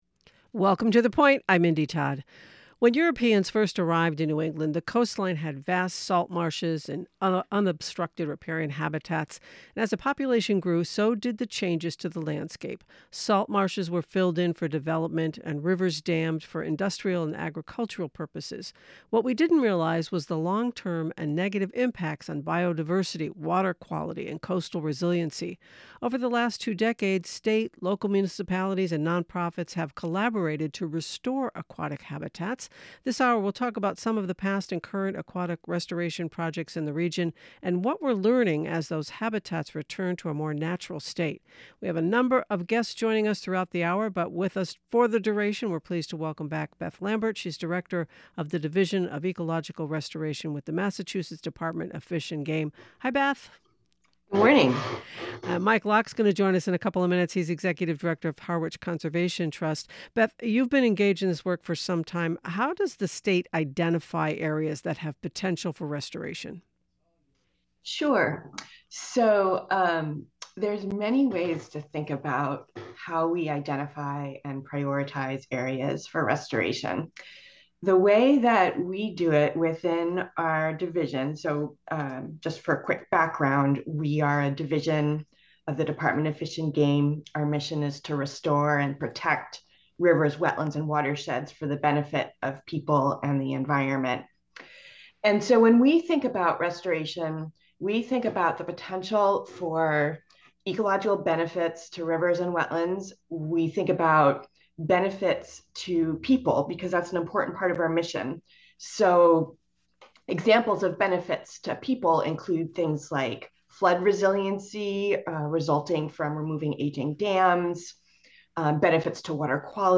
An interview with foraging expert and author